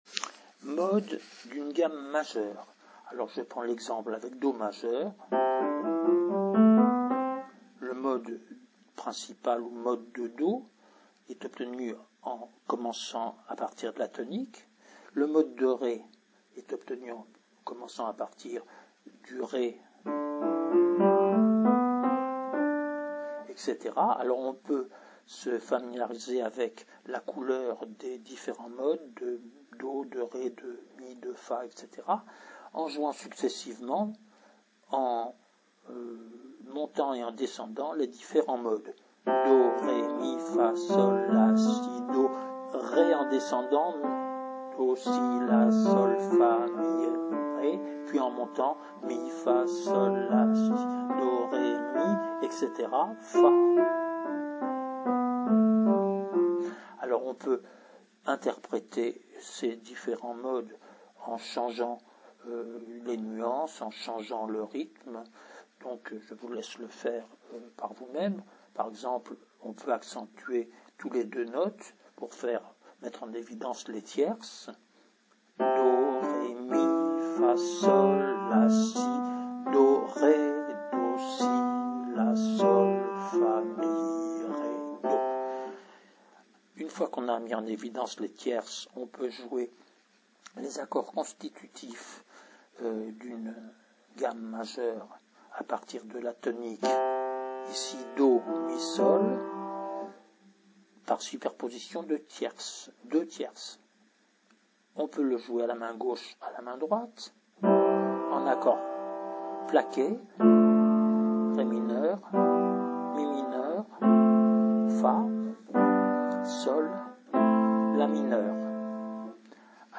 Exercice 2 : Accords sur les degrés des modes des gammes majeures